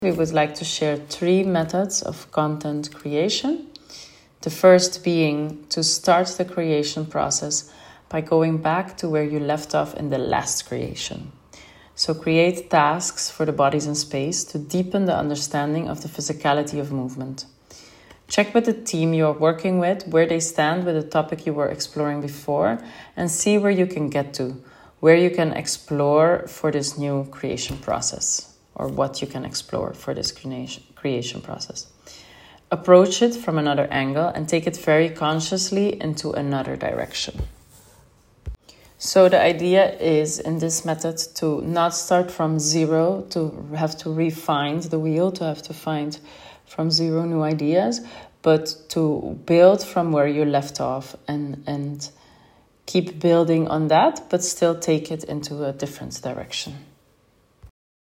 Title Method of Content Creation 1 ContentConcept audio conversation statement Type Audio Tier 6.